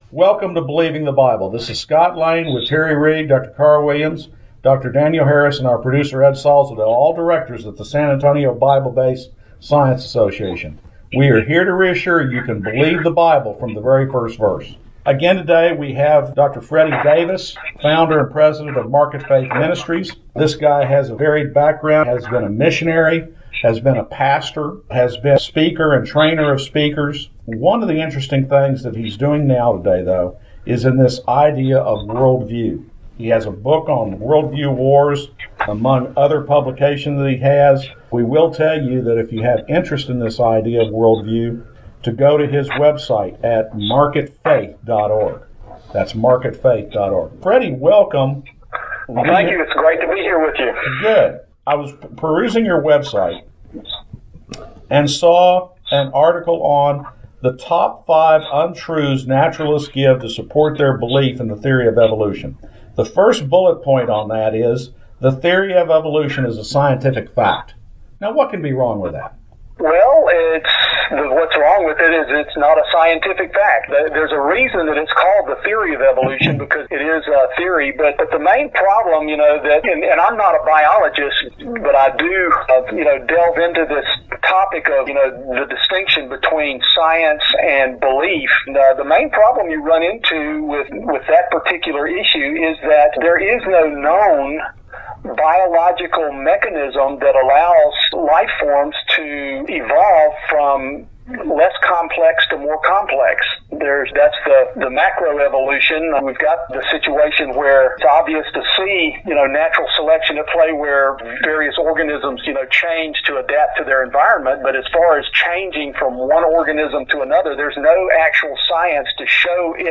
Believing the Bible Interview #2 - MarketFaith Ministries